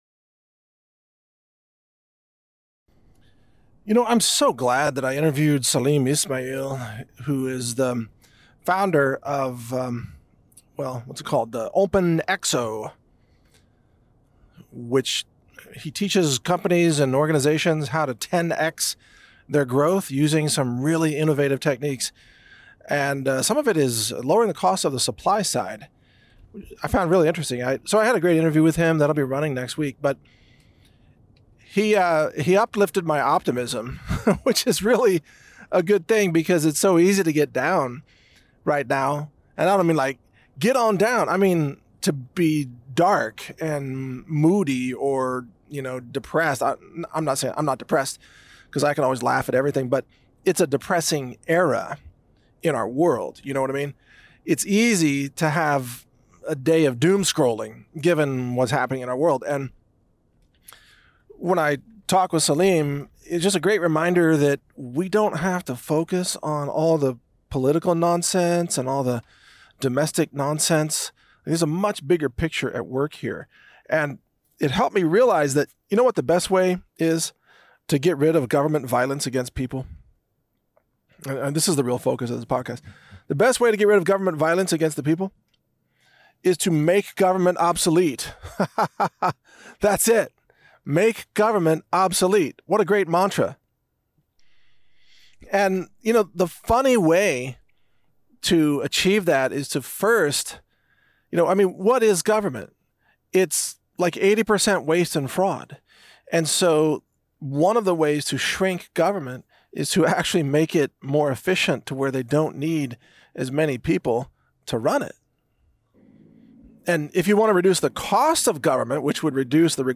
- Interview with Salim Ismail and Government Efficiency (0:00)